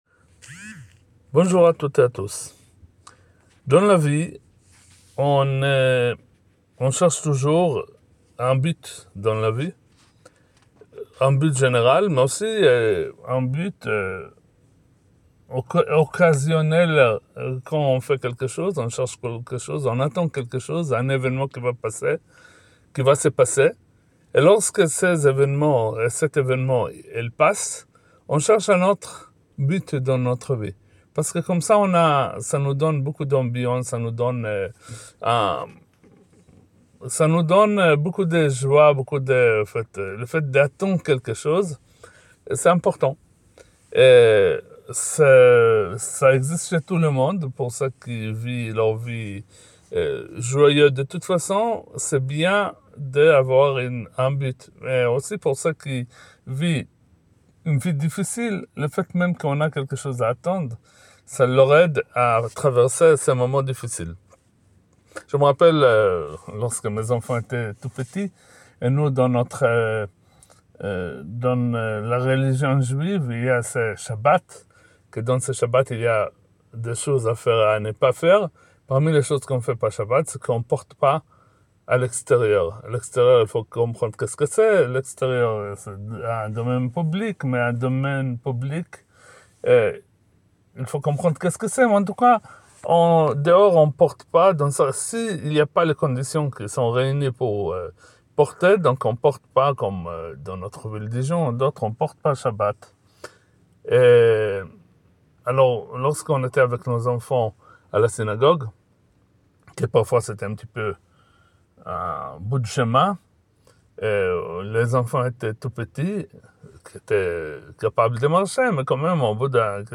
le commentaire de la paracha